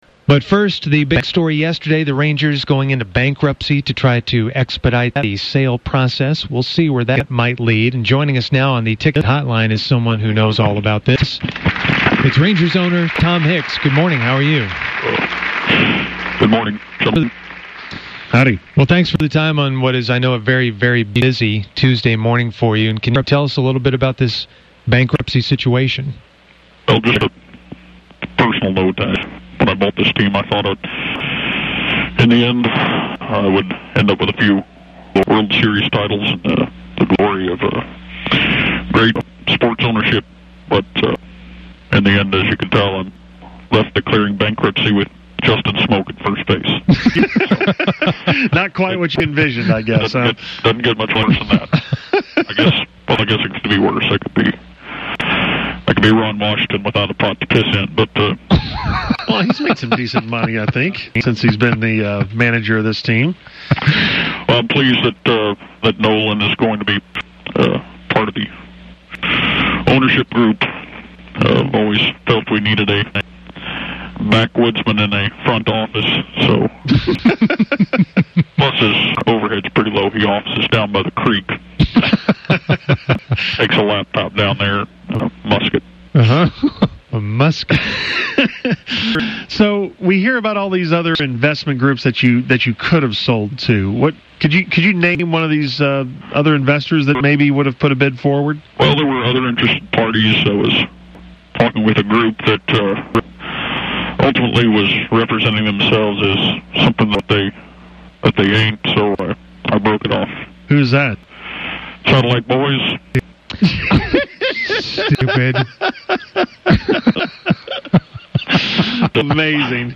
Fake Tom Hicks – Rangers Bankruptcy
Fake Tom Hicks talks about the bankruptcy of the Rangers.